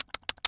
mouse_eating_02.wav